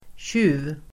Uttal: [²tj'u:v-]